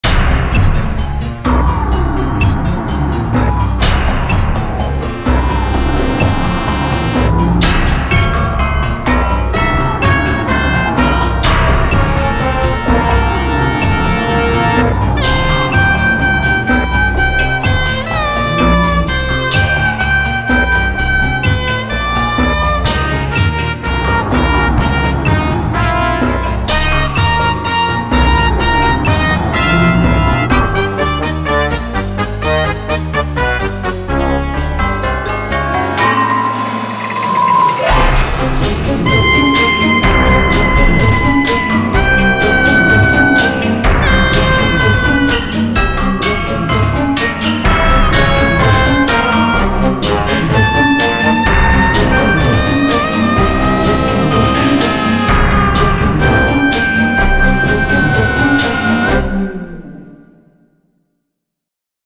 • Качество: 224, Stereo
без слов
инструментальные
пугающие
тревожные
волынка
странные
неоклассика
Классика в современной обработке